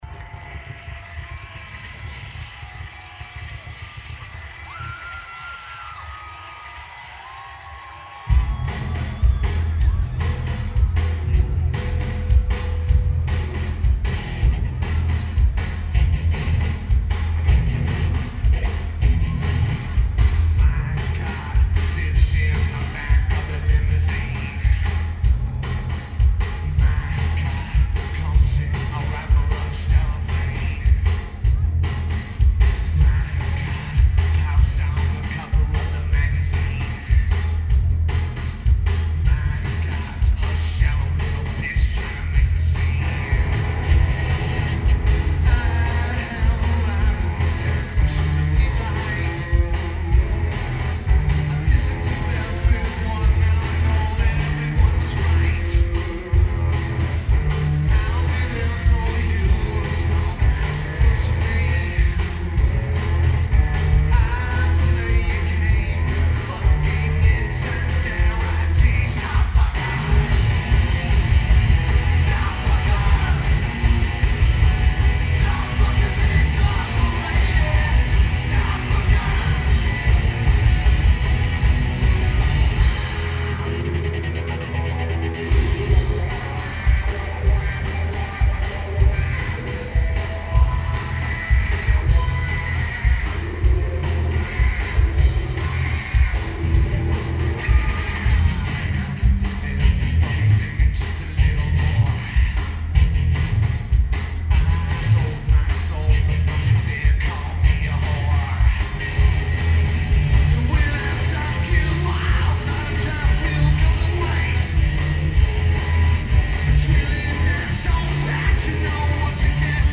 Thomas & Mack Center
Lineage: Audio - AUD (DPA 4061's + CSBox + Sony PCM-M1)
The recording is exceptional.